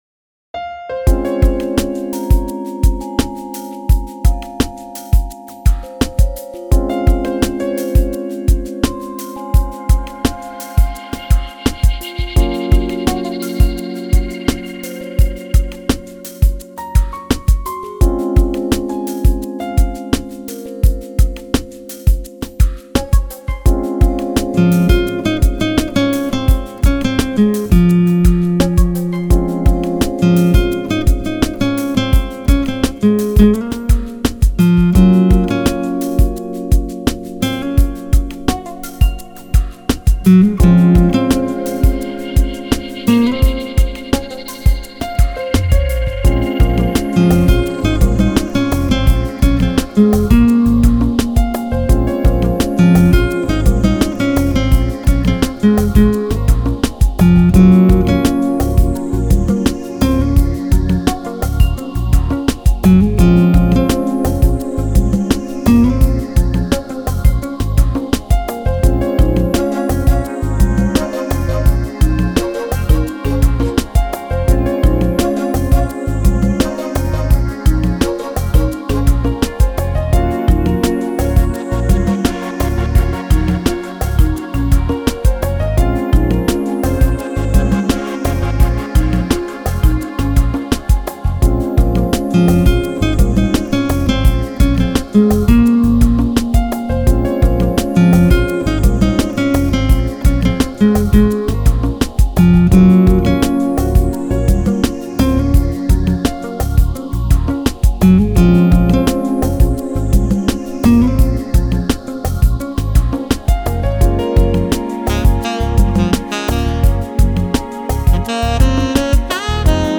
Genre: Smooth Jazz, Chillout, Longe